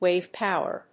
音節wáve pòwer